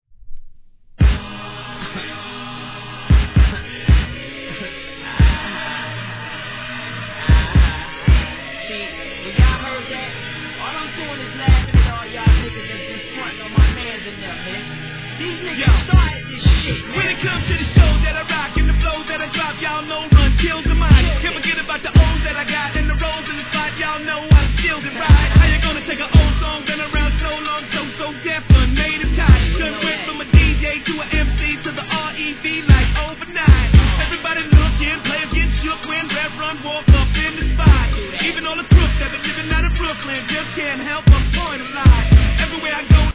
HIP HOP/R&B
OLD SCHOOL色を上手く残したBEATに壮大なスケールを感じさせるコーラス